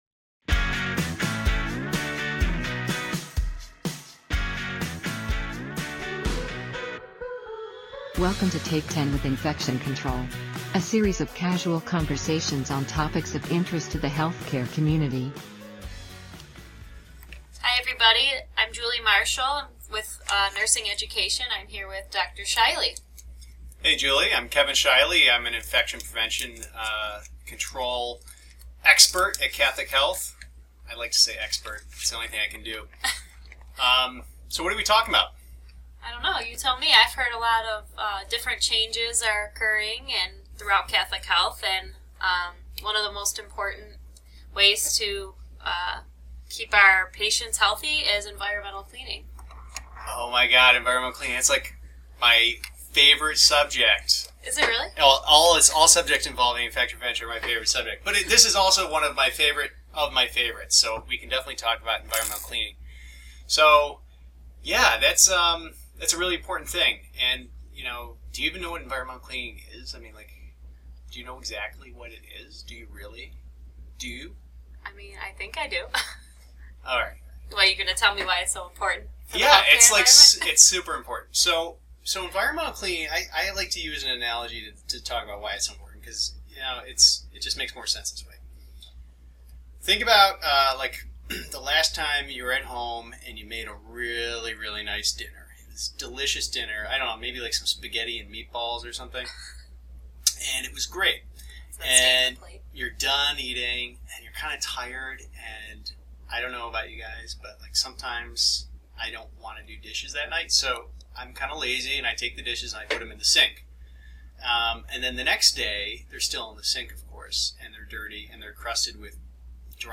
A series of casual conversations